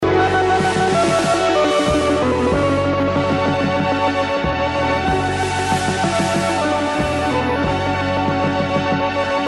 Instrument 1
Also mal abgsehen davon, dass das Melodieinstrument in beiden fällen extrem unsauber klingt (vielleicht ist das auch gewollt?), würde ich auf Synthesizer/Keyboard tippen.
Na wenn, dann ist das ein verfremdetes oder mit zittrigen Händen gespieltes Theremin.